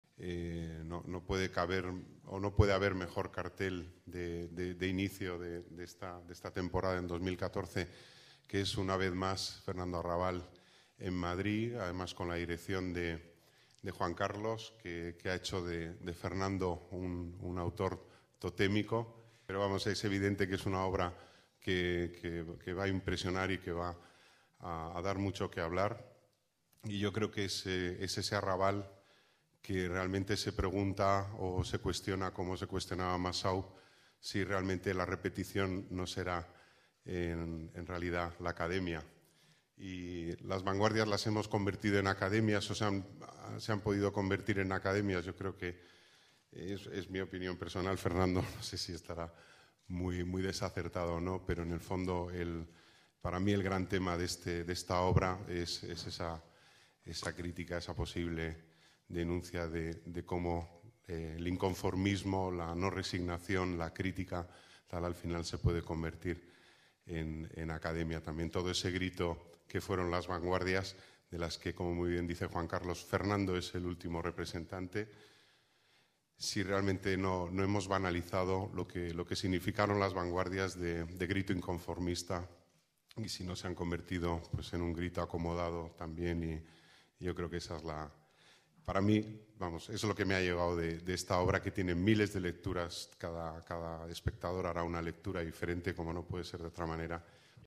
Nueva ventana:Declaraciones del delegado de las artes, Deportes y Turismo, Pedro Corral: Obra Dalí vs. Picasso